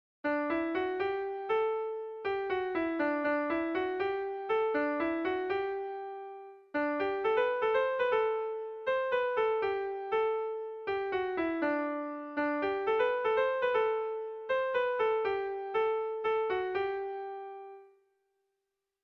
Kontakizunezkoa
Seikoa, ertainaren moldekoa, 4 puntuz (hg) / Lau puntukoa, ertainaren moldekoa (ip)
A1A2BB